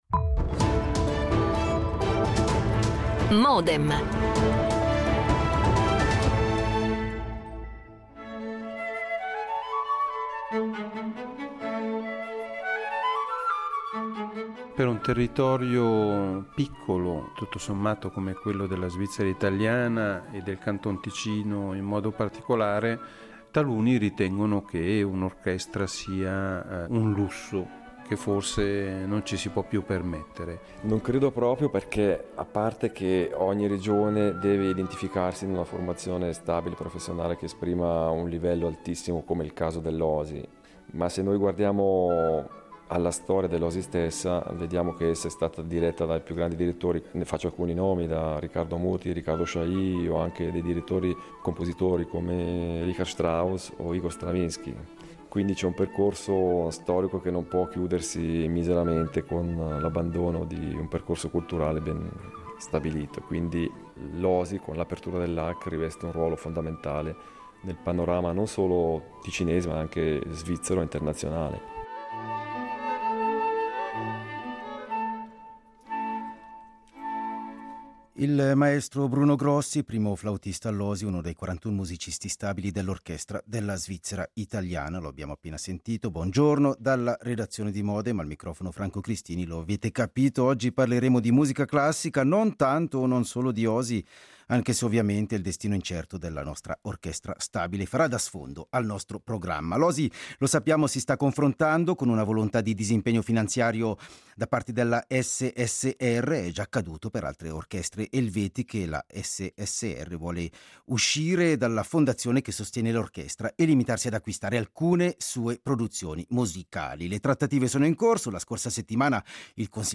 Ne ritrae la figura in un reportage che proponiamo nella prima parte del programma.